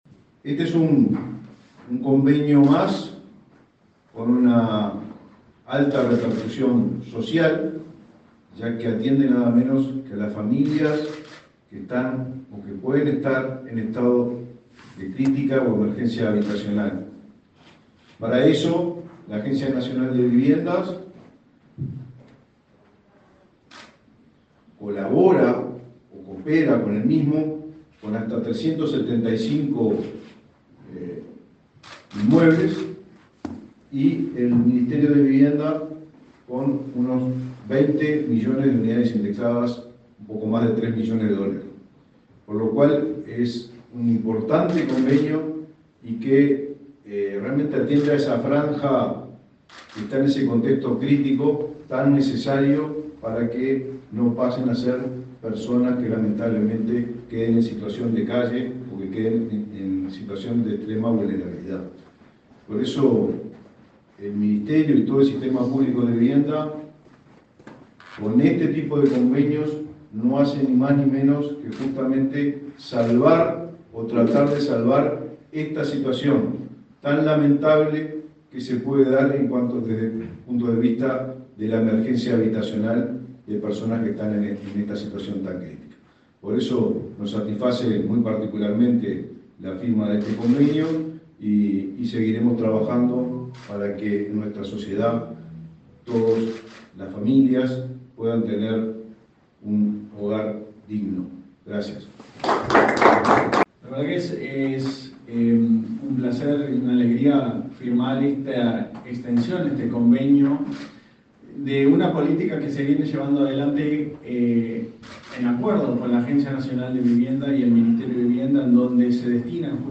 Palabras de autoridades en acto de firma de convenio sobre vivienda